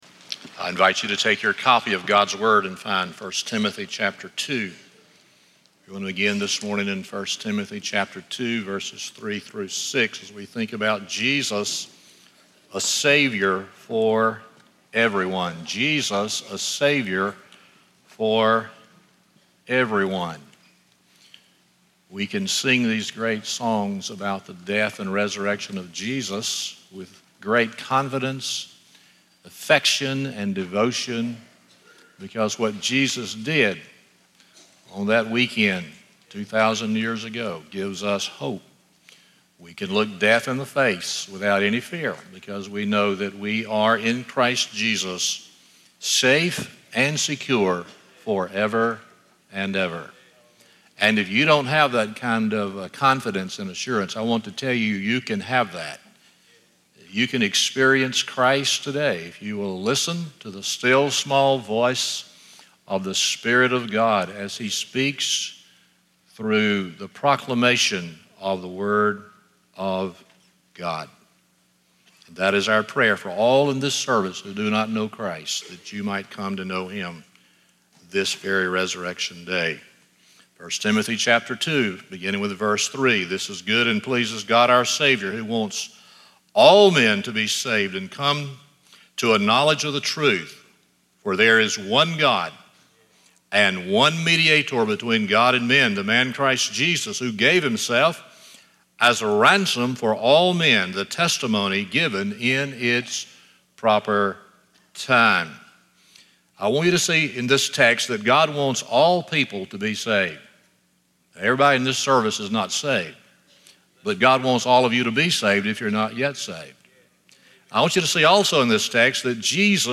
1 Timothy 2:3-6 Service Type: Sunday Morning 1.